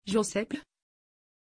Pronunciation of Joseph
pronunciation-joseph-tr.mp3